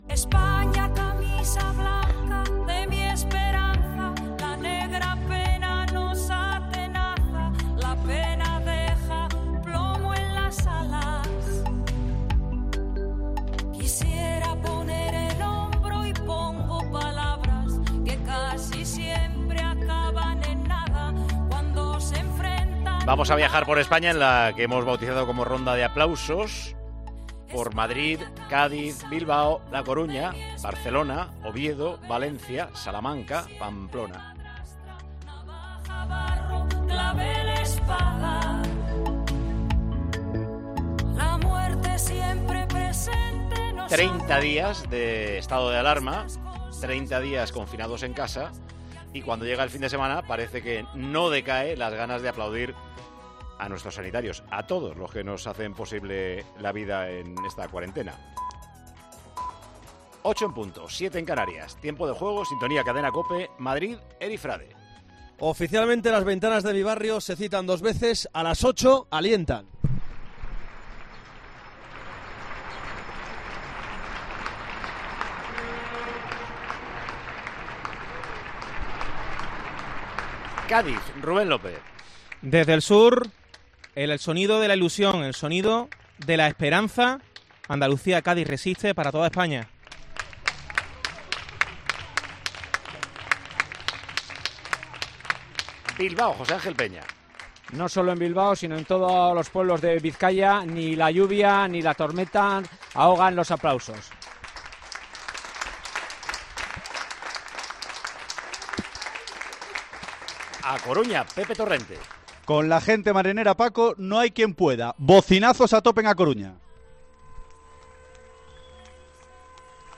Las sirenas, protagonistas en la ronda de aplausos de este domingo, 12 de abril de 2020
Los cuerpos de seguridad de Pamplona se han puesto de acuerdo para sonar en Tiempo de Juego. Sumamos los aplausos desde 10 ciudades de España.